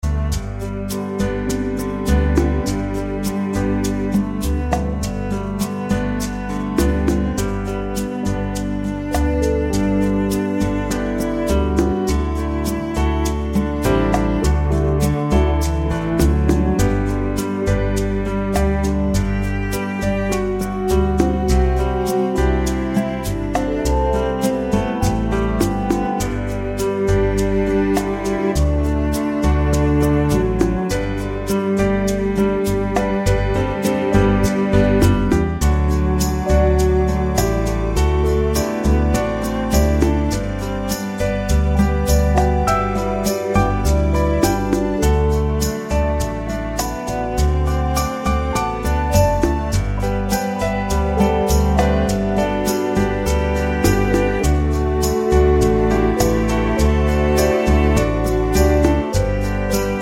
Up 5 Semitones For Female